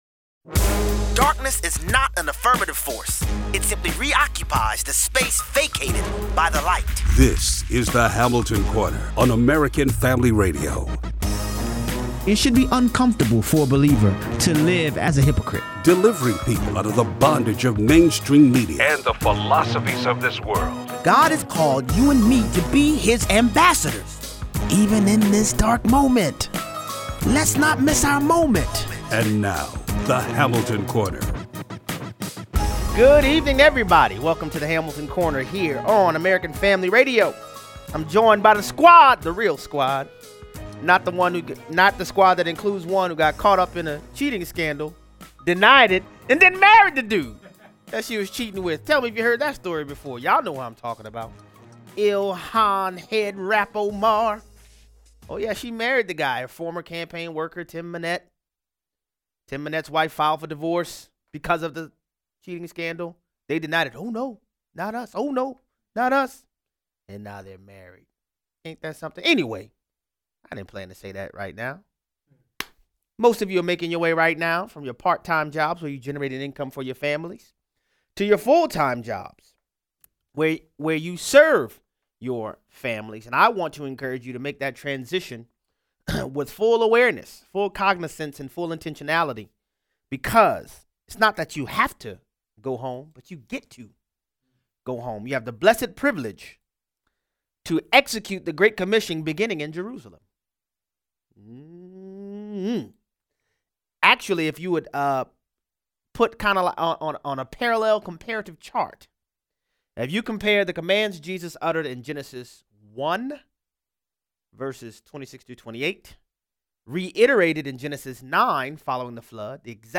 Do you think the Congress members actually read it before it passed? Callers weigh in.